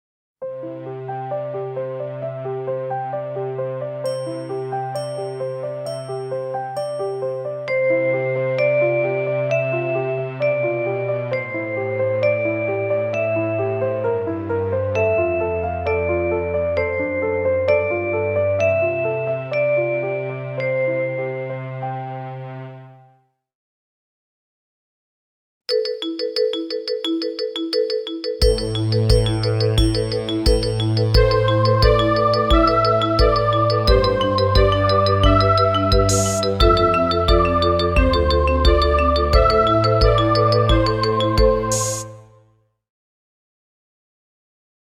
Extra soundmixbegeleidingen voor tutors instrument: